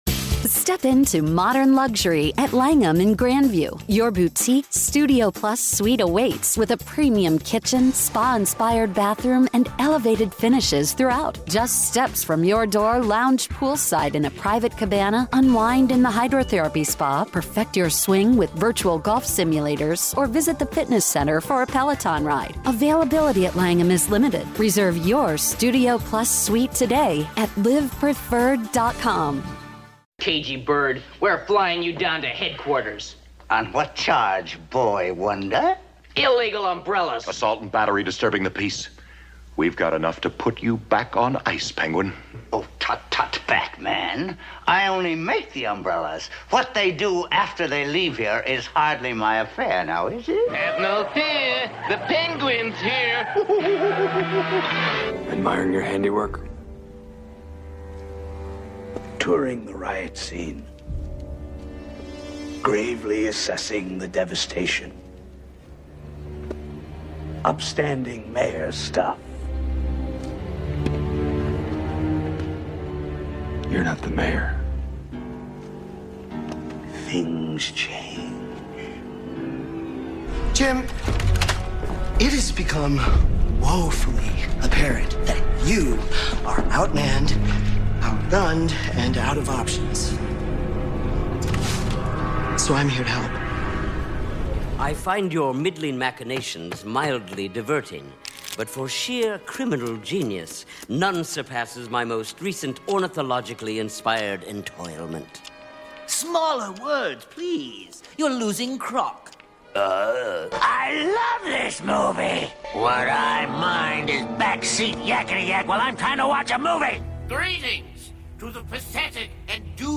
Apologies for the nasally voice.